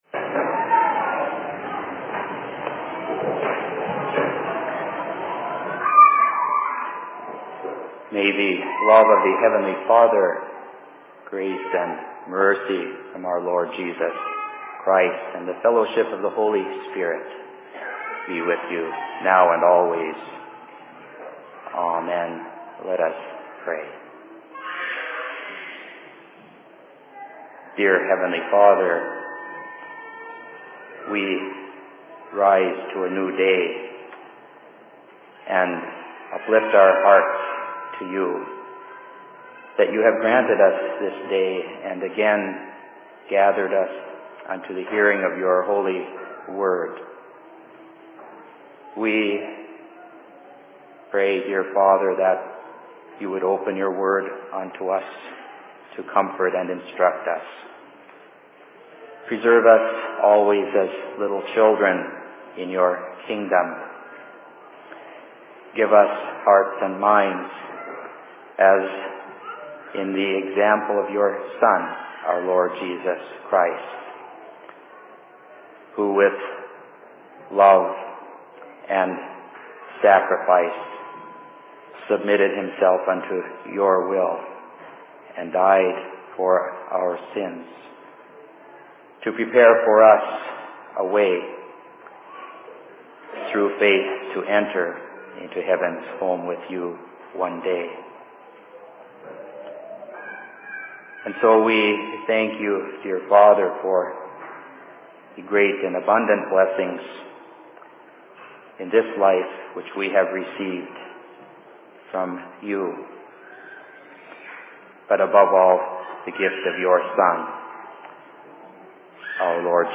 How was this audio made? Location: LLC Minneapolis